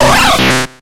Cri de Kabutops dans Pokémon X et Y.